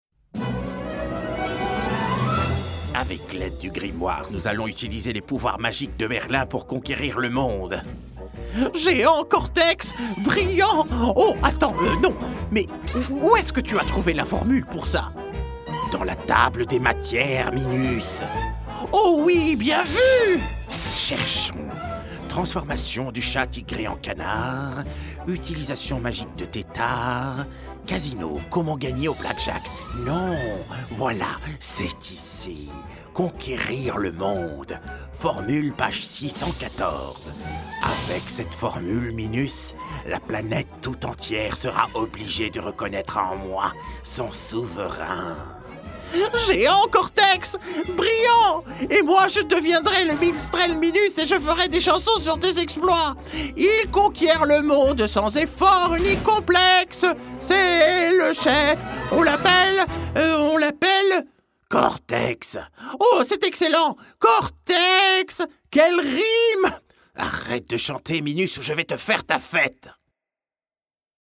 Voix off
33 - 43 ans - Baryton Ténor